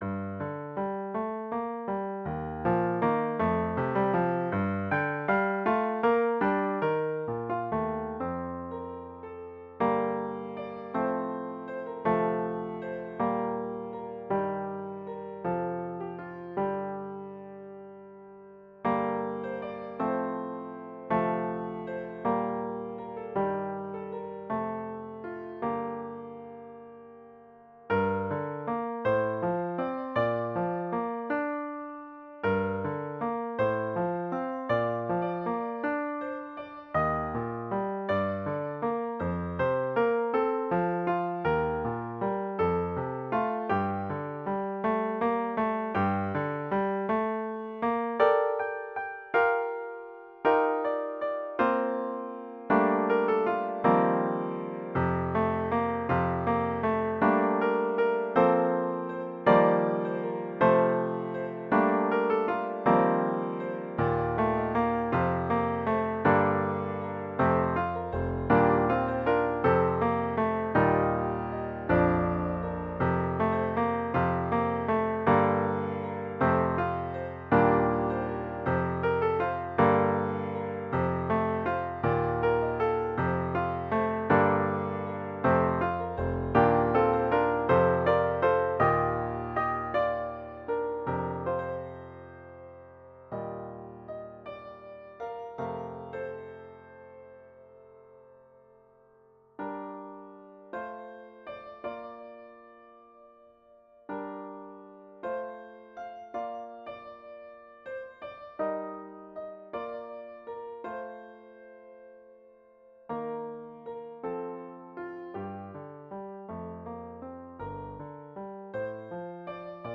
Arranged in the key of G minor.
Voicing/Instrumentation: Vocal Solo